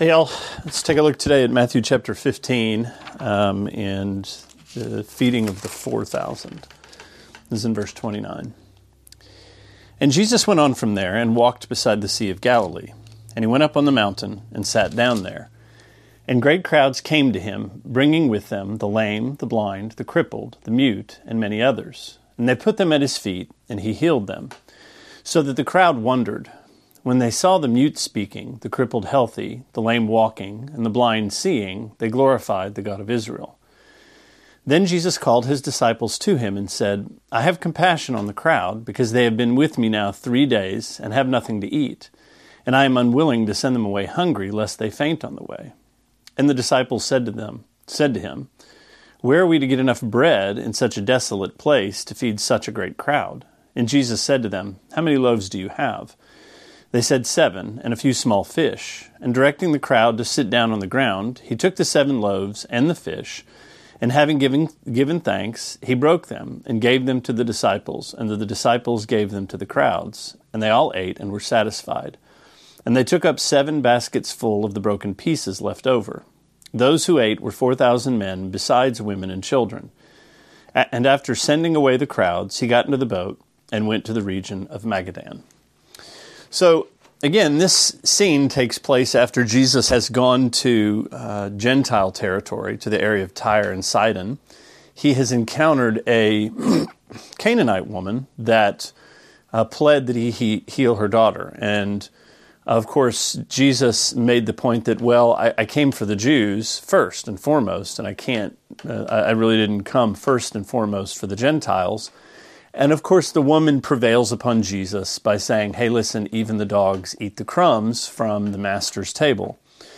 Sermonette 3/4: Matthew 15:29-39: A Feast of Crumbs